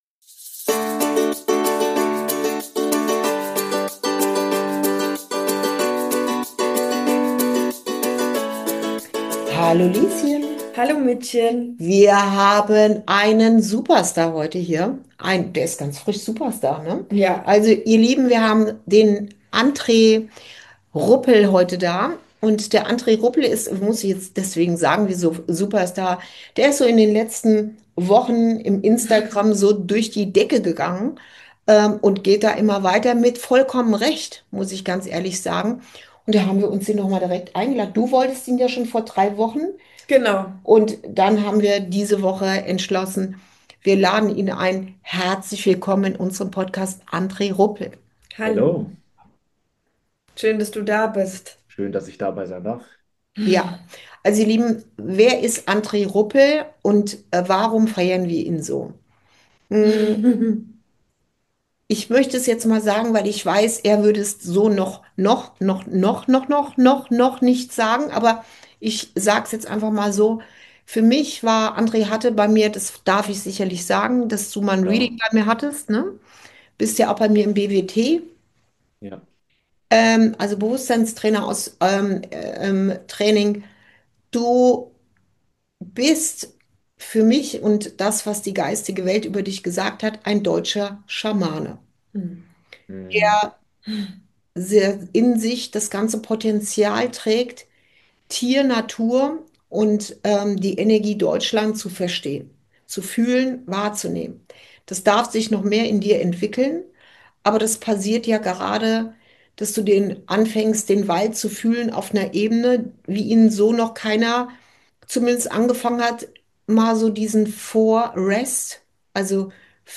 057: Natur ist überall - Auch in dir! ~ Inside Out - Ein Gespräch zwischen Mutter und Tochter Podcast